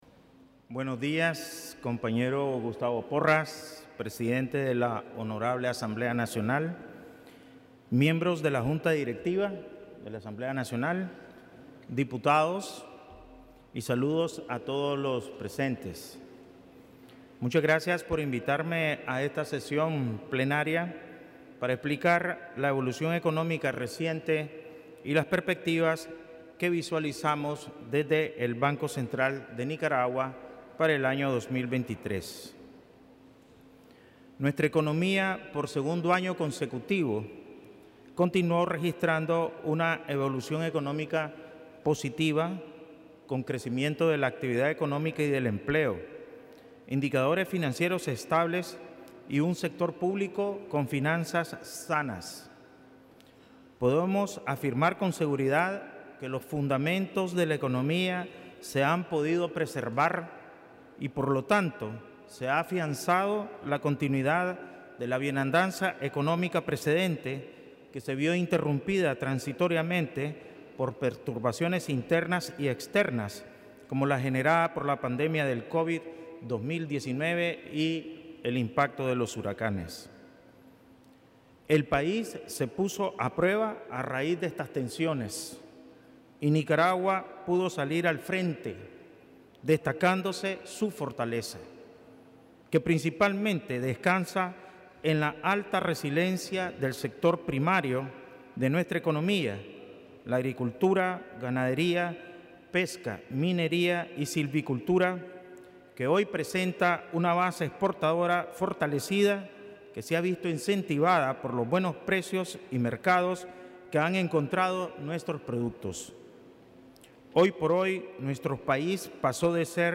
Presidente del BCN presenta evolución y perspectivas económicas en Asamblea Nacional
El Presidente del Banco Central de Nicaragua (BCN), Ovidio Reyes R., realizó el 31 de enero de 2023 una presentación en la Asamblea Nacional sobre la evolución económica reciente de Nicaragua y las perspectivas para el presente año.